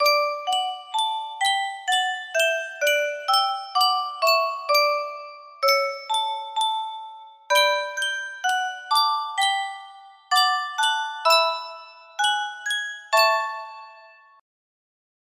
Yunsheng Music Box - Beethoven Violin Concerto 5800 music box melody
Full range 60